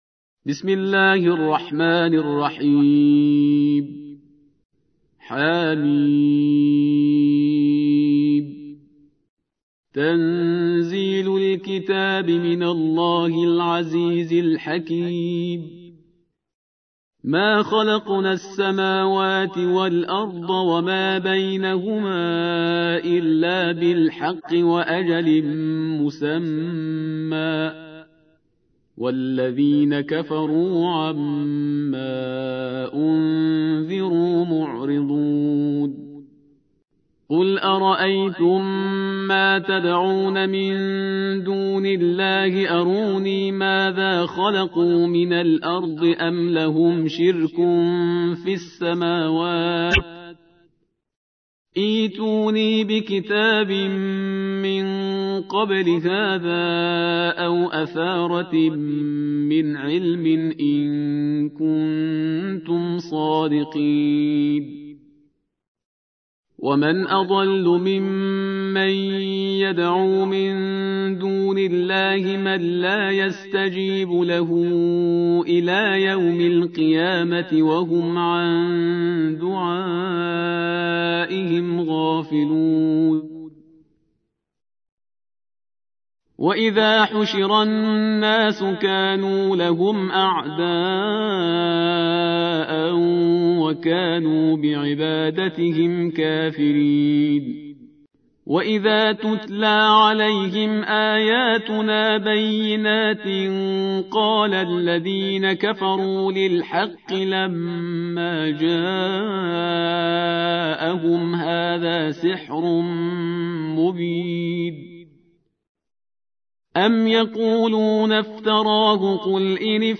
46. سورة الأحقاف / القارئ